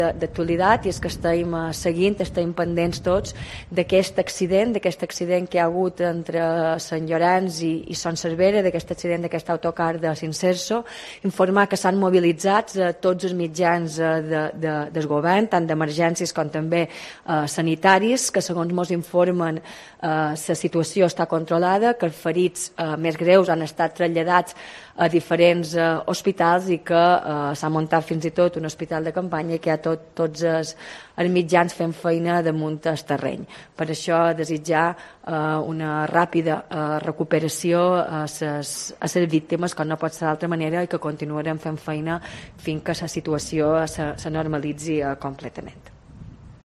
Marga Prohens, presidenta del Govern de les Illes Balears se refiere al accidente de autobús